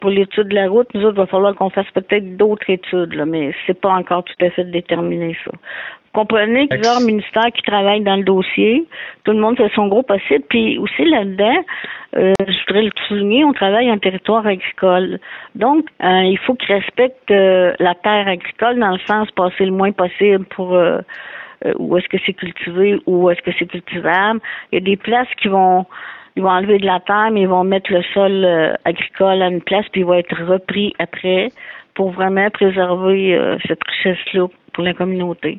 En entrevue, la mairesse, Denise Gendron, a mentionné qu’il y a une étude en ce moment pour la suite des choses.